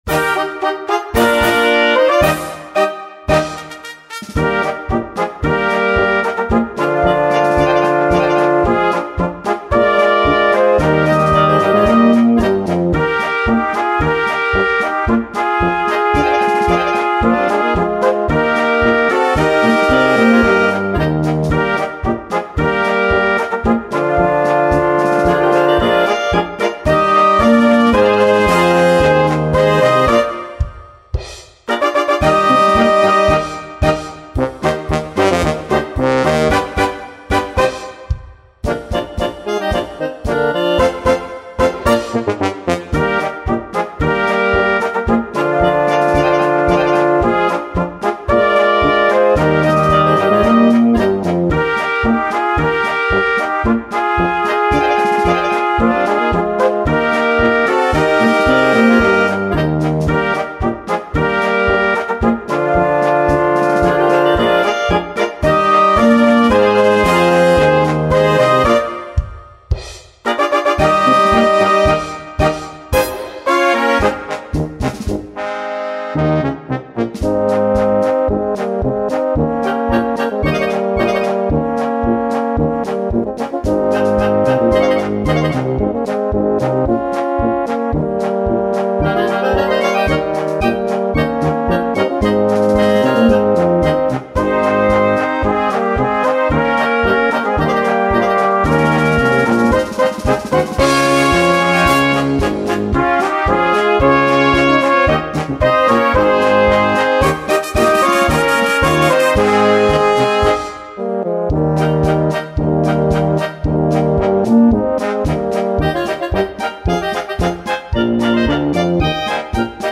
Na klar! A zünftige Musik!